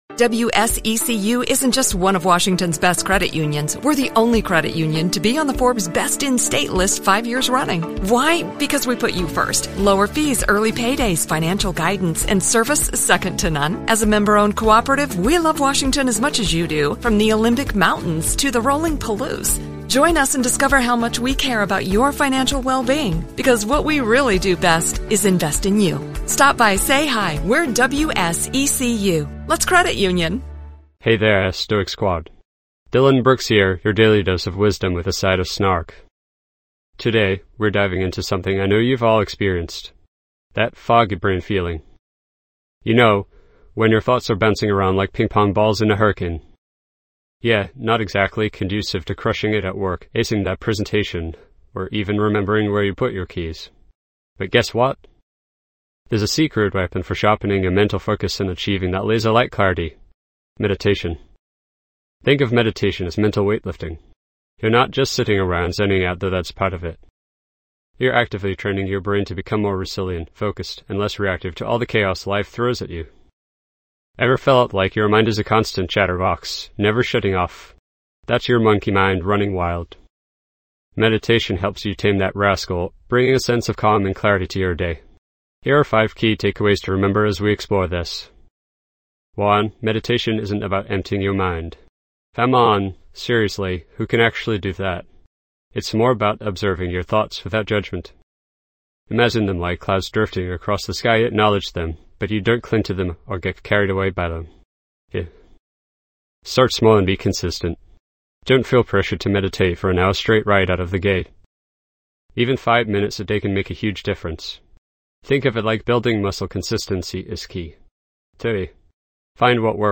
Mental Clarity:. Guided Meditations for Focus
This podcast is created with the help of advanced AI to deliver thoughtful affirmations and positive messages just for you.